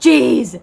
Worms speechbanks
Bummer.wav